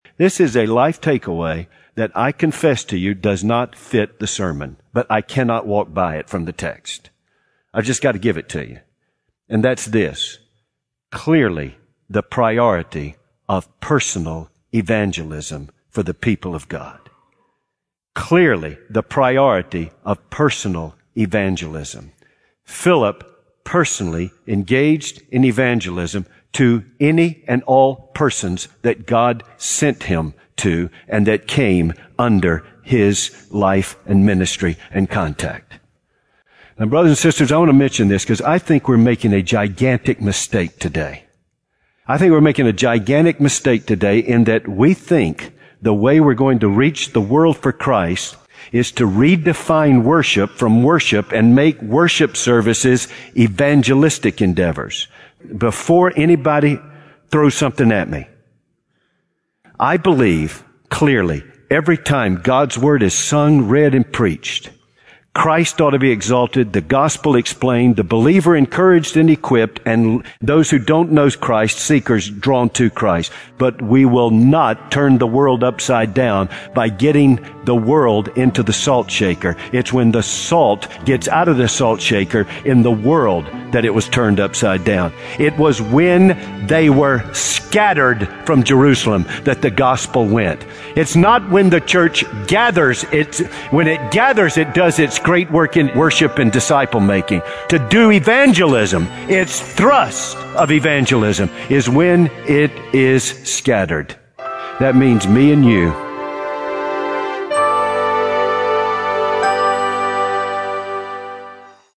Please listen to this two-minute audio clip I excerpted from a sermon I heard on the radio yesterday